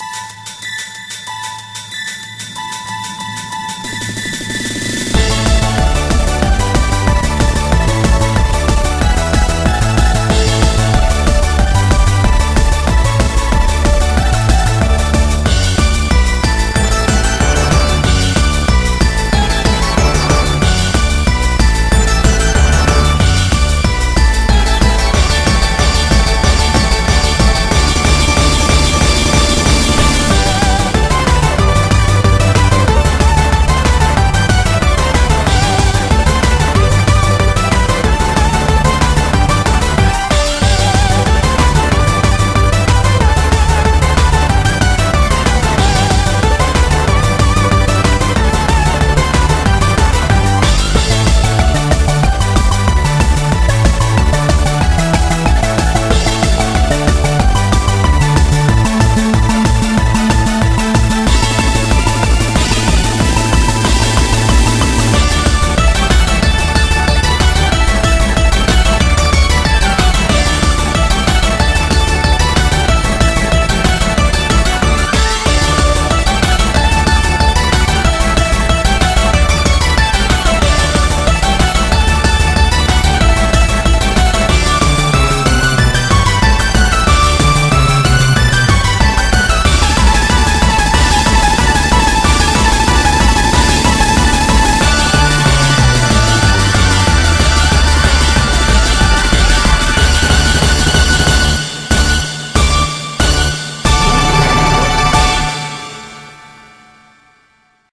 BPM186
Audio QualityPerfect (High Quality)